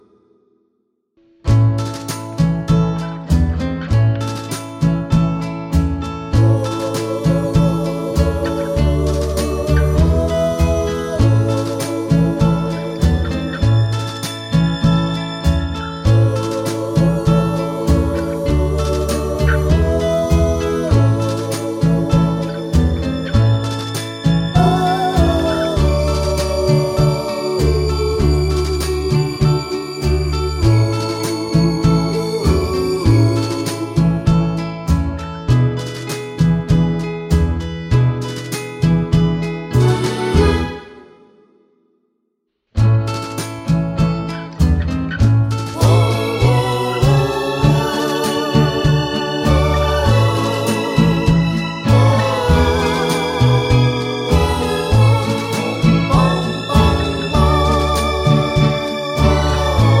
Minus Two Part Harmony Pop (1960s) 2:49 Buy £1.50